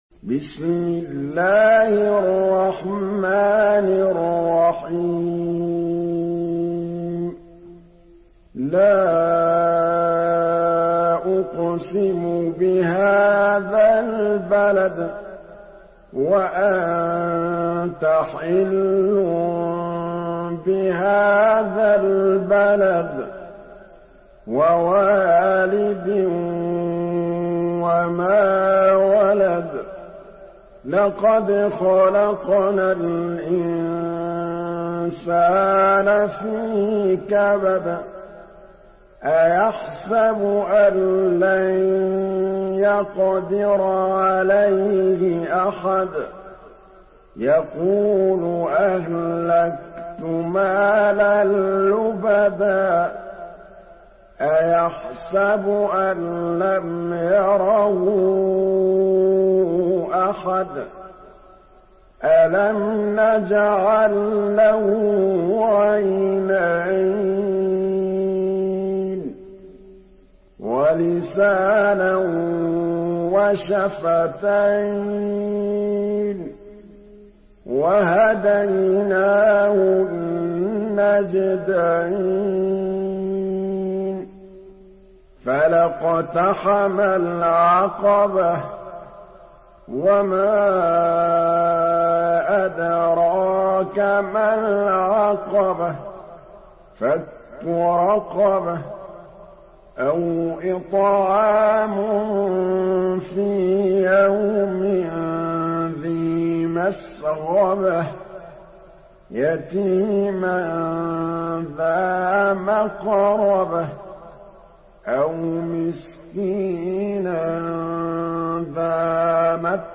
Surah আল-বালাদ MP3 by Muhammad Mahmood Al Tablawi in Hafs An Asim narration.
Murattal Hafs An Asim